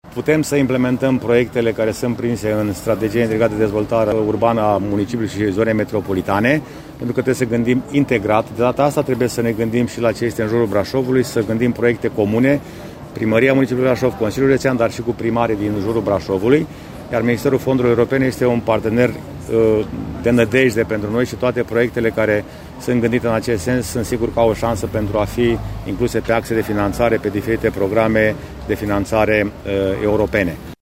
Acesta și-a lansat candidatura ieri, în cadrul evenimentului de prezentare a candidaților Partidului Național Liberal din zona metropolitană Brașov și din Țara Bârsei, la care a fost prezent și ministrul Fondurilor Europene, Ioan Marcel Boloș.
Candidatul PNL Brașov la funcția de primar al Brașovului, George Scripcaru spune că administrația trebuie să gândească în mod integrat: